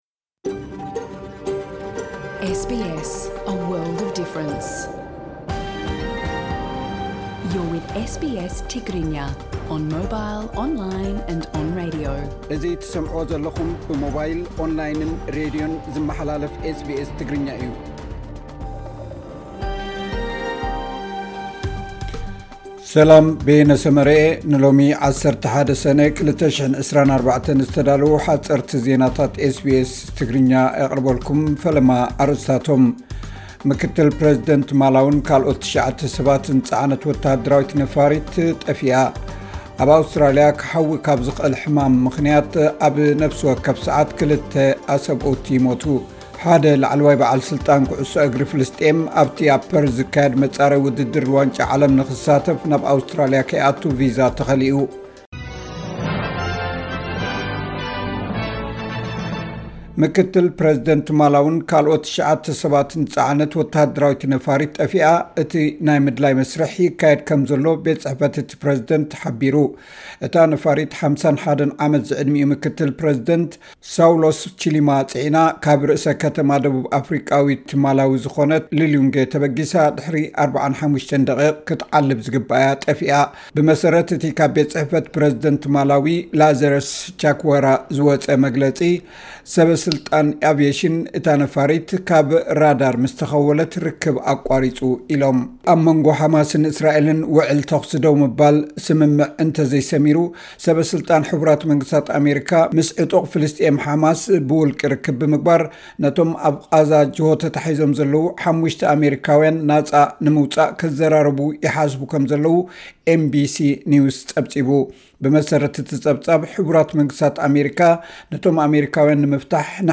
ሓጸርቲ ዜናታት ኤስ ቢ ኤስ ትግርኛ (11 ሰነ 2024)